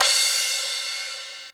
Index of /90_sSampleCDs/Best Service ProSamples vol.15 - Dance Drums [AKAI] 1CD/Partition B/HH 001-052